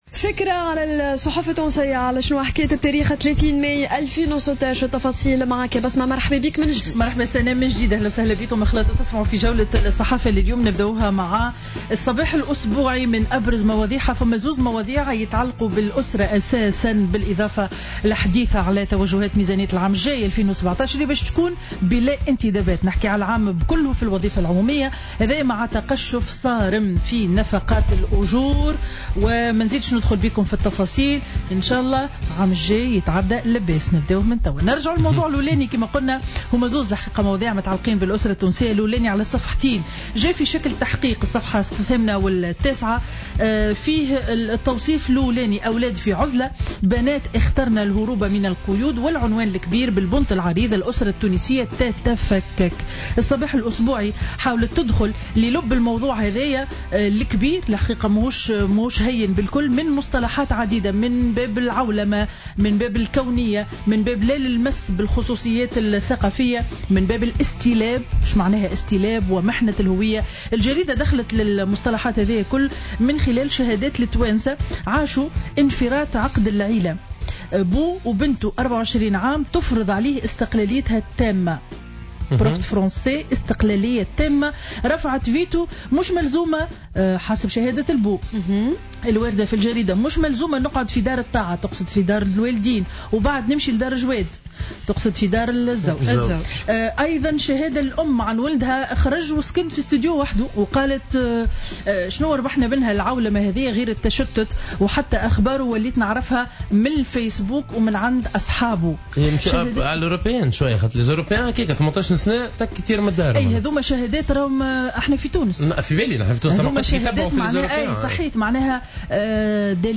Revue de presse du lundi 30 mai 2016